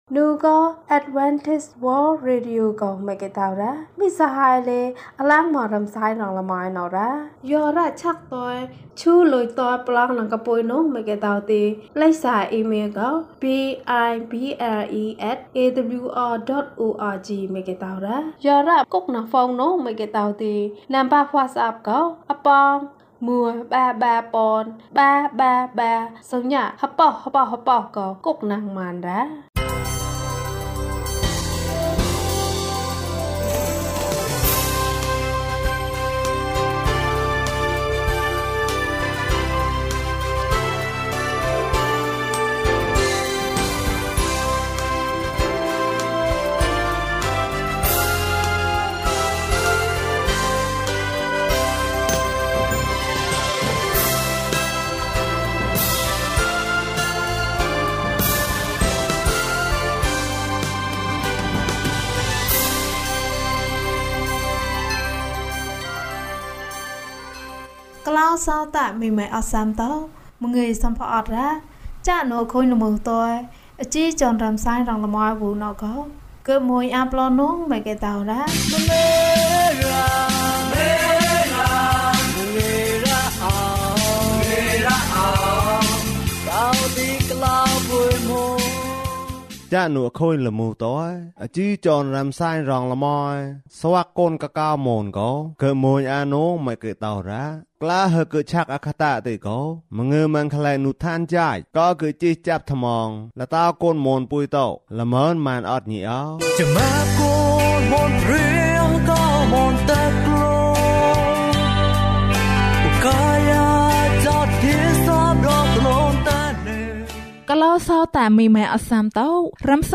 တံငါသည်။ ကျန်းမာခြင်းအကြောင်းအရာ။ ဓမ္မသီချင်း။ တရားဒေသနာ။